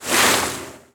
• 循环
SFX
RA3_AUDolph_movLoopC.mp3